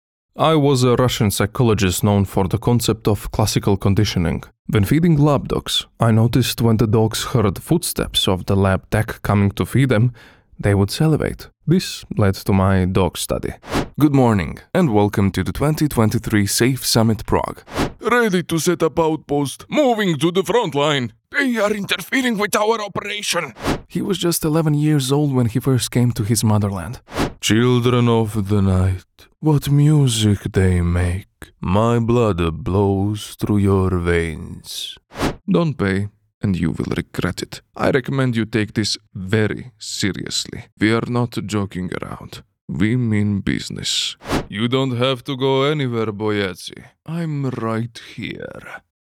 Male
20s, 30s, 40s
English Demo Reel.mp3
Microphone: Neumann TLM103
Audio equipment: Sound booth, Audient iD14, mic stand, metal pop filter